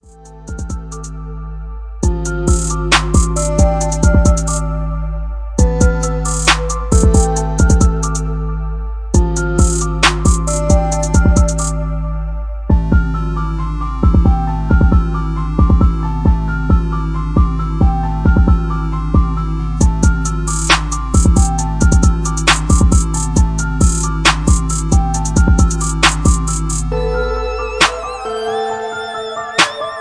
Booming 808's